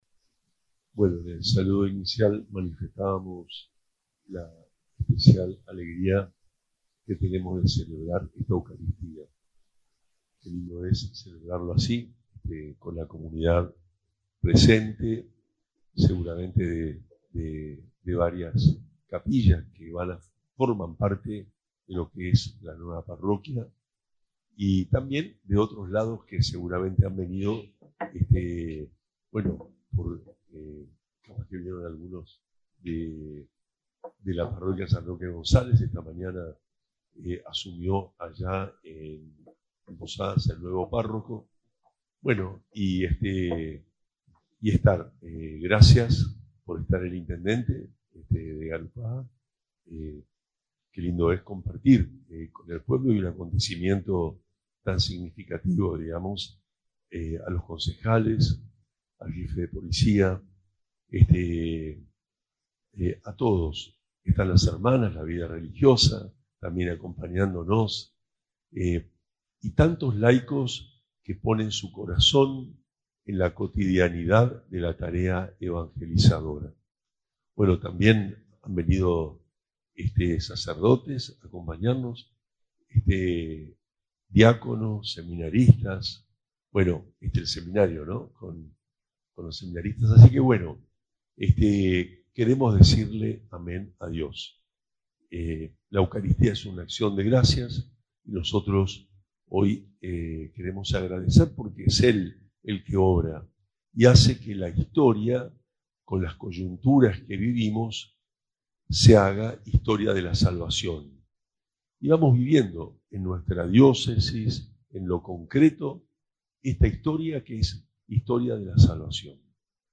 HOMILIA-OBISPO-PARROQUIA-MARIA-AUXILIADORA-GARUPA.mp3